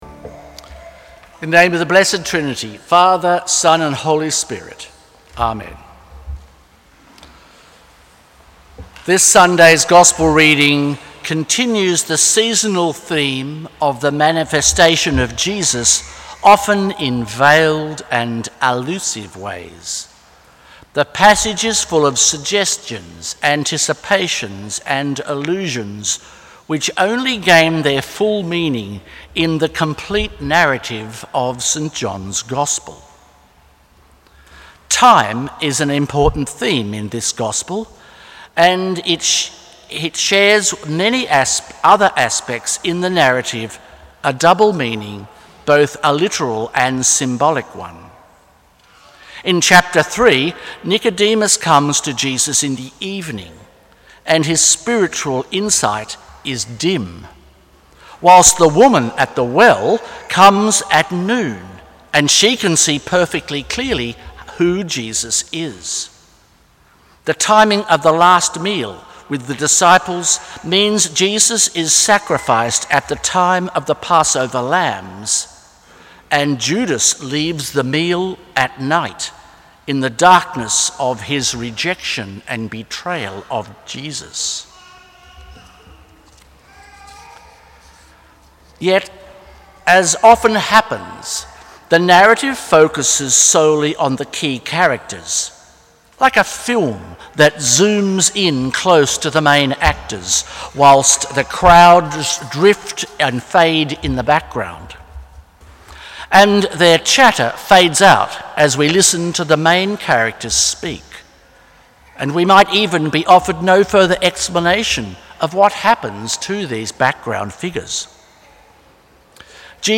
Sermons
Second Sunday after the Epiphany